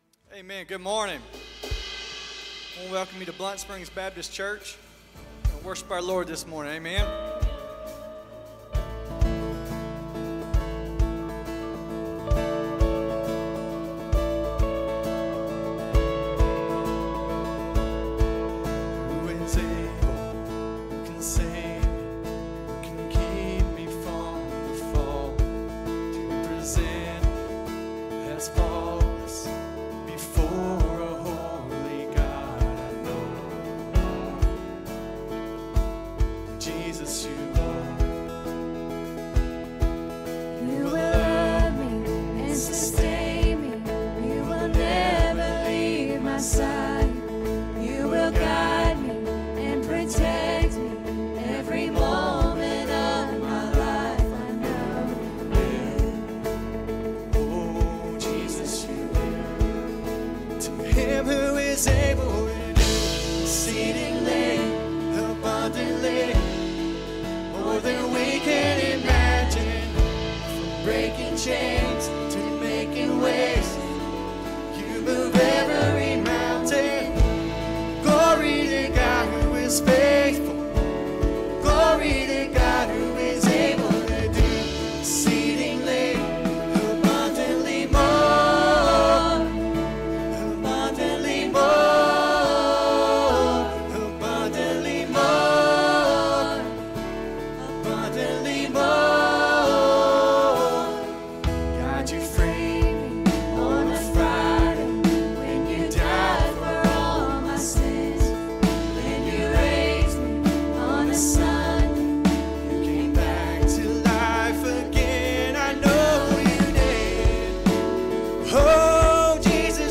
Sunday morning worship service and sermon.